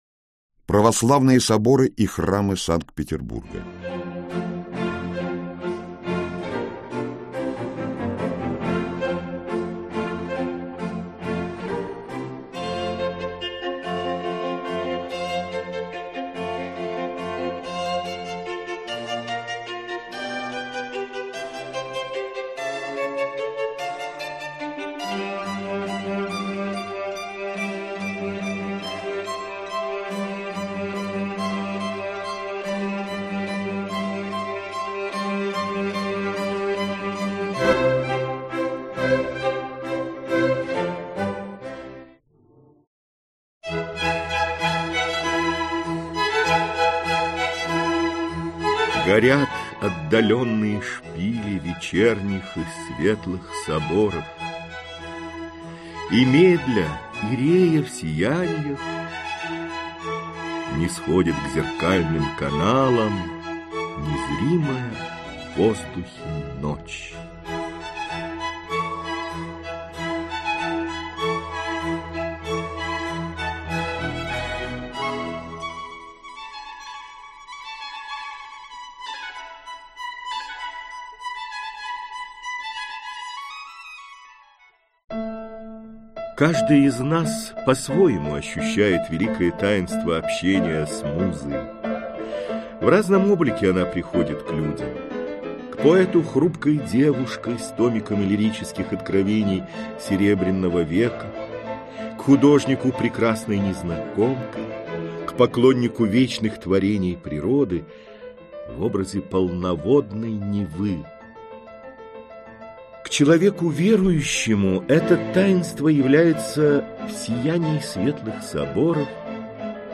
Аудиокнига Православные соборы и Храмы Санкт-Петербурга. Путеводитель | Библиотека аудиокниг